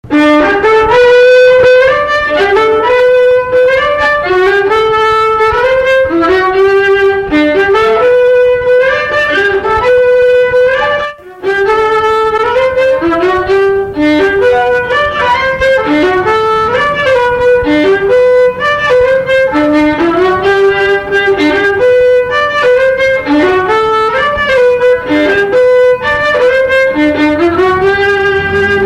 Instrumental
danse : quadrille
Pièce musicale inédite